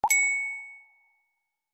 messenger-notification.mp3